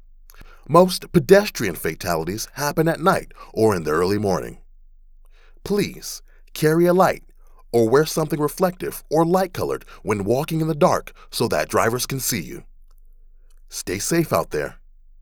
Commuter Advertising Audio Ad
commuter_advertising_audio_commercial.wav